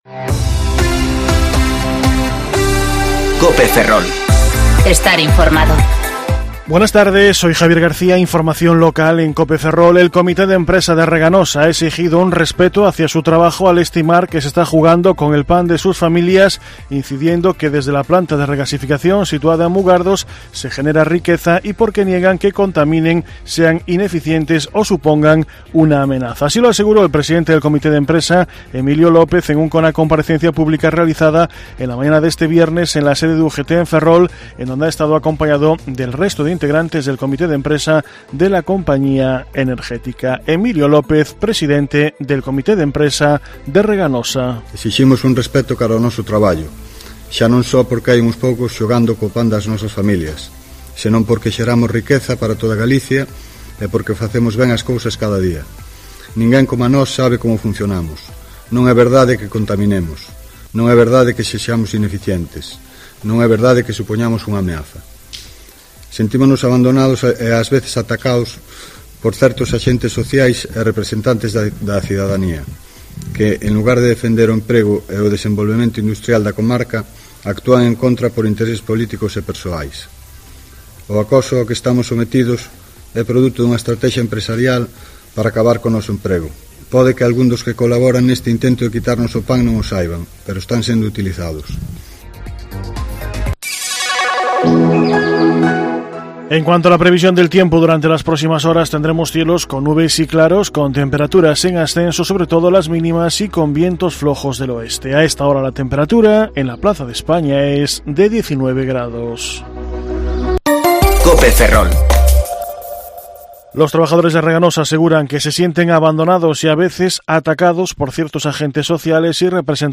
Informativo Mediodía Cope Ferrol 4/10/2019 (De 14.20 a 14.30 horas)